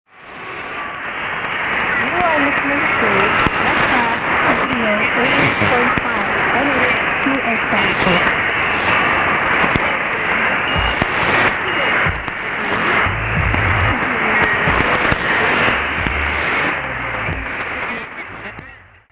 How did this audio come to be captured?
Cross Radio from Pohnpei, Micronesia on 4755 kHz shortwave.